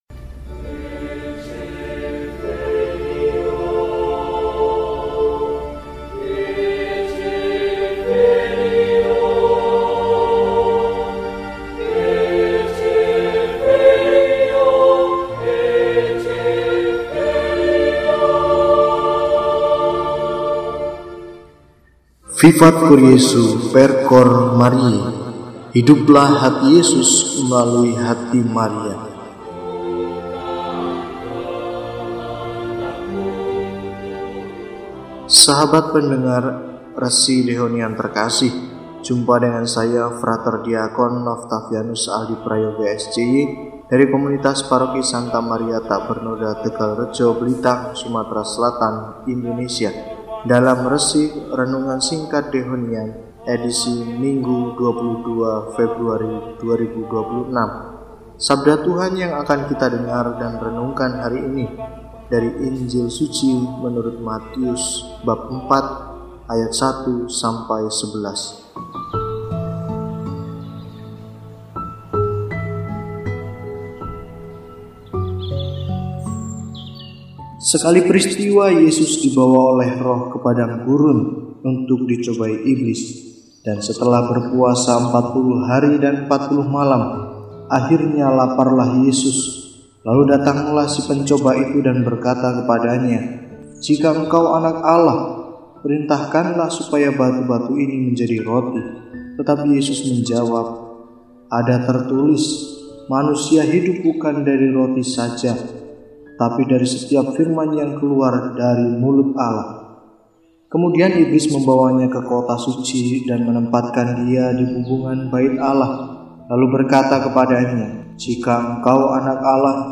Minggu, 22 Februari 2026: Hari Minggu Prapaskah I – RESI (Renungan Singkat) DEHONIAN